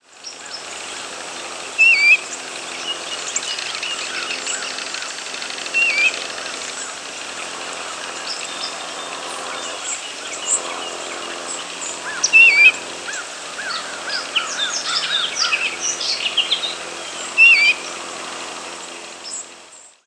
Eastern Bluebird diurnal flight calls
Odd call from bird in flight. American Crow and White-throated and Song Sparrows singing in the background.